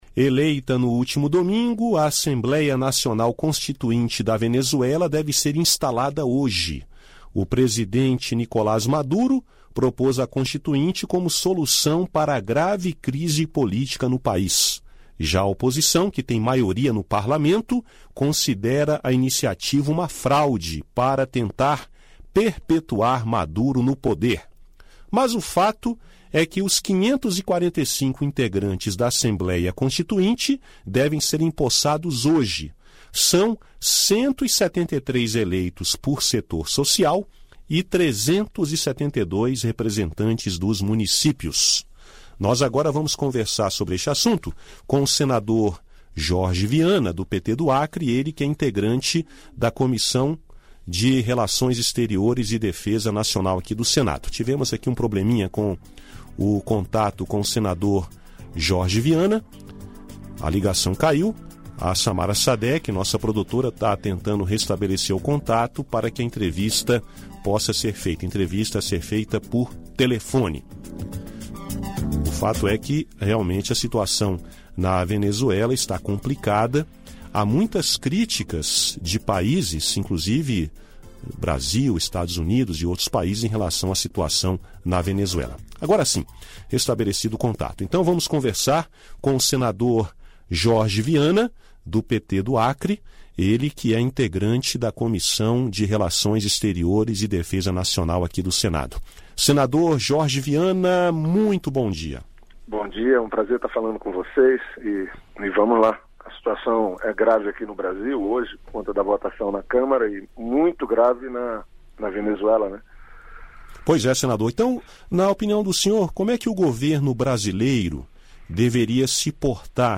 Em entrevista à Rádio Senado, o senador comentou a instalação da Assembleia Nacional Constituinte da Venezuela, marcada para esta quarta-feira (2).